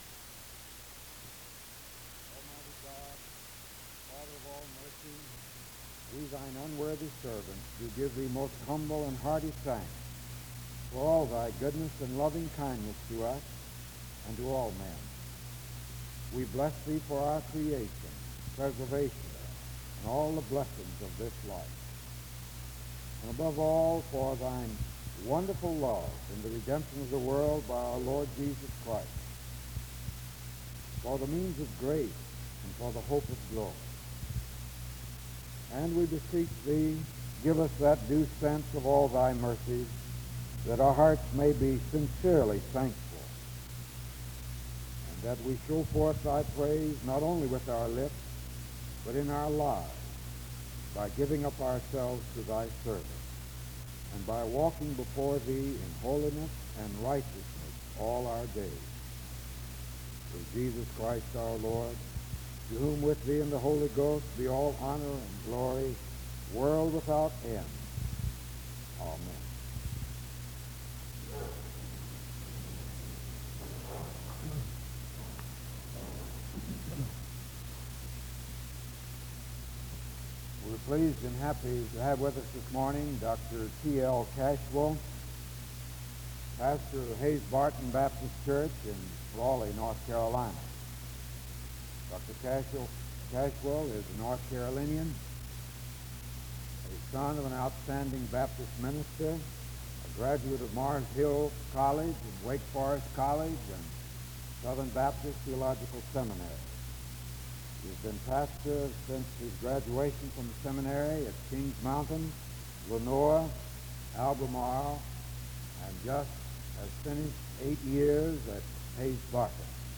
The service begins with a prayer (0:00-1:10).
The service continues with a period of singing (3:23-6:37).
The service concludes in song (22:03-22:46).